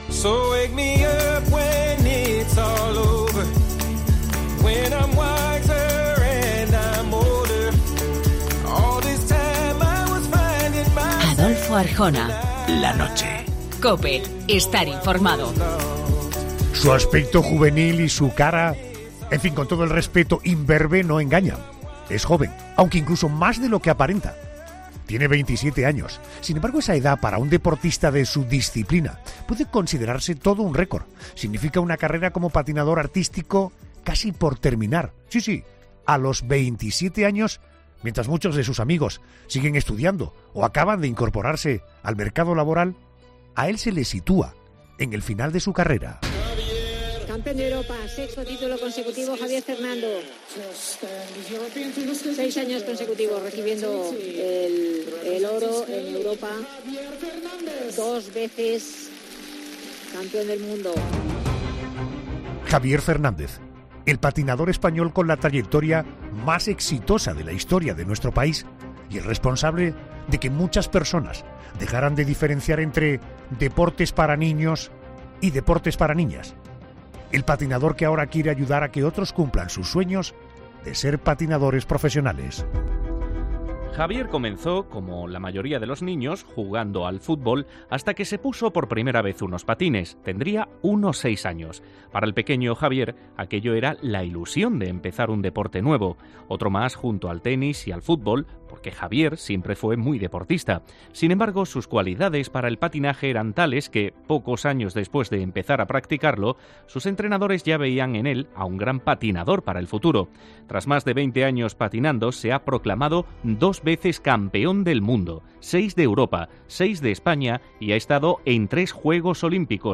El patinador olímpico repasa su carrera en 'La Noche de COPE'